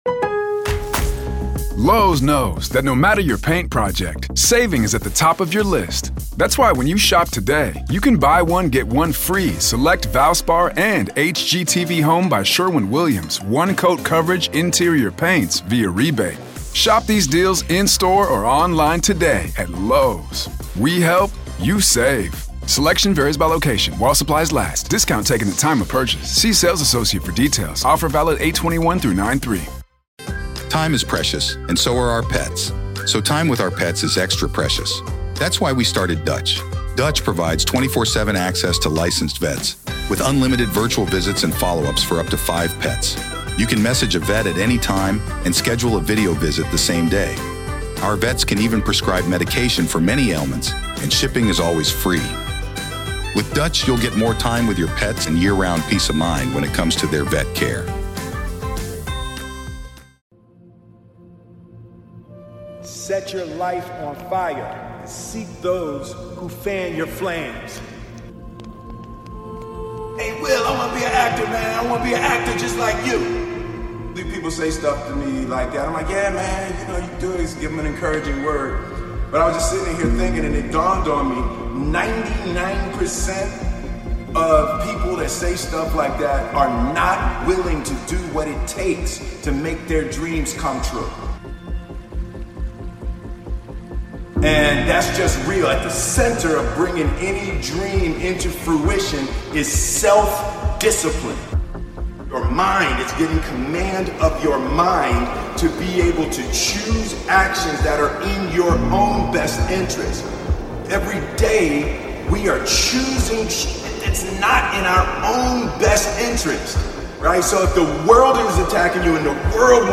BE REAL WITH YOURSELF - Will Smith Best Motivational Speech.